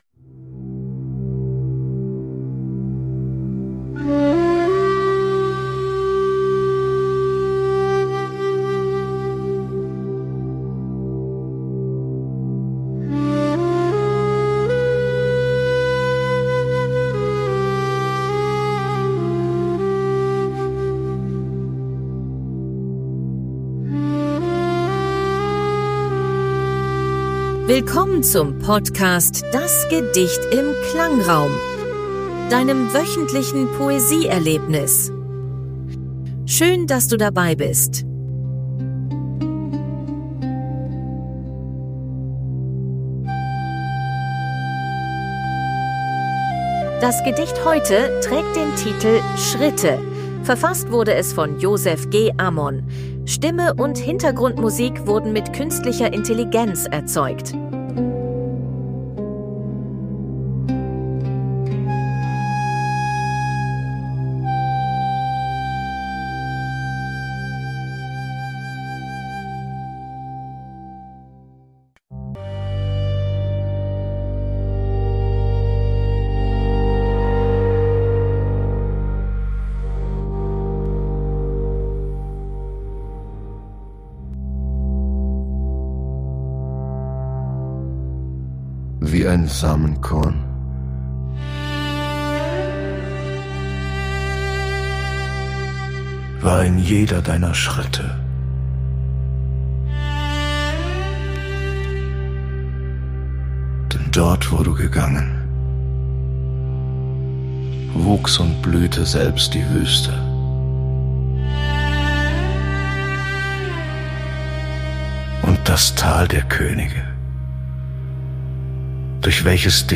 Stimme und Hintergrundmusik
wurden mit KI erzeugt. 2025 GoHi (Podcast) - Kontakt: